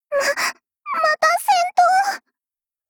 Cv-10147_battlewarcry.mp3